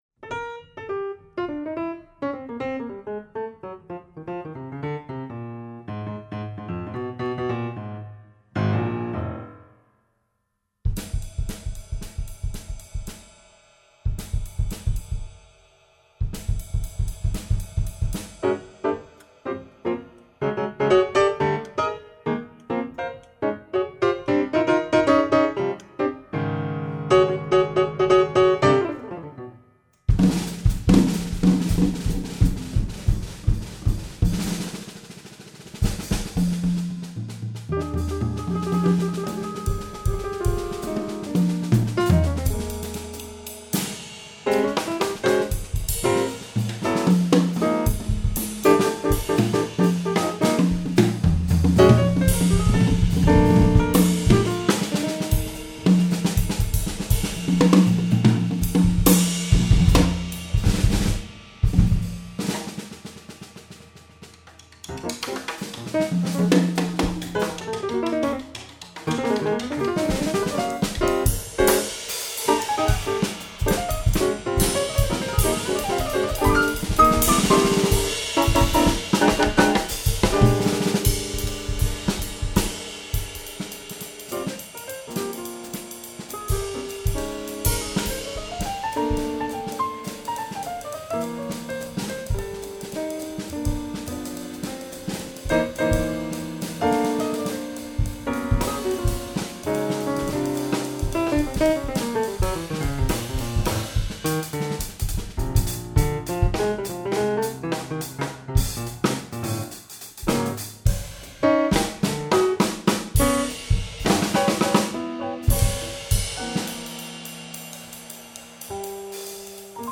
Piano
Double Bass